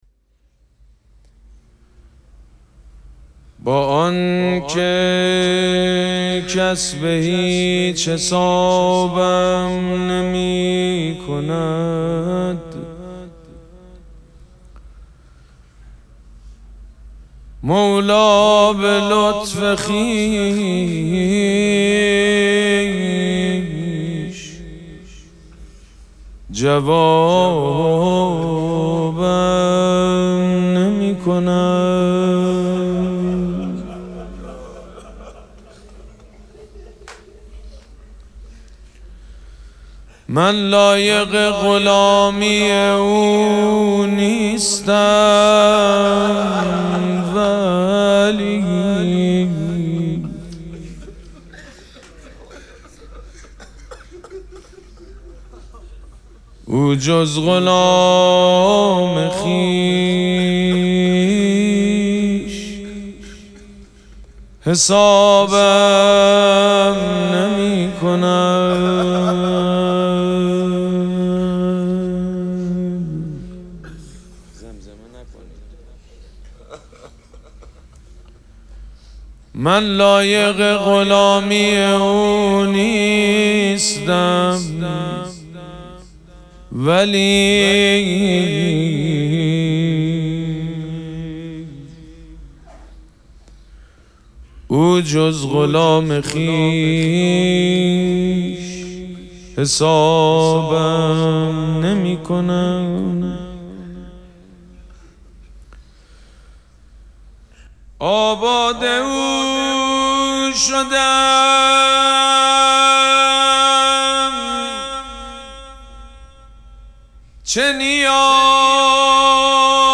دانلود فایل صوتی شعرخوانی مراسم شهادت امام صادق (ع) 1404 حاج سید مجید بنی فاطمه با آن که کس به هیچ حسابم نمیکند
شهادت امام صادق (ع) 1404